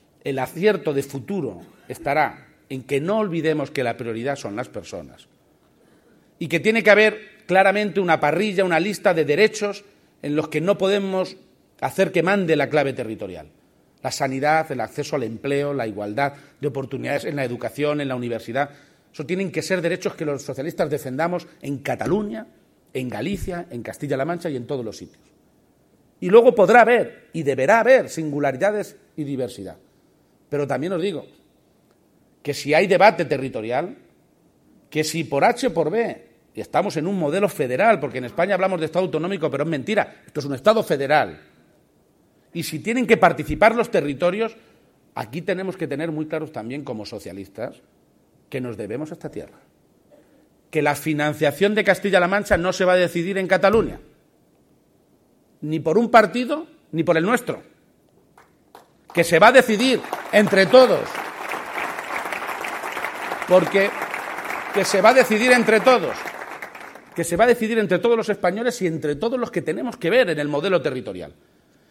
INAUGURACION DEL 17 CONGRESO PROVINCIAL DEL PSOE DE CIUDAD REAL
Cortes de audio de la rueda de prensa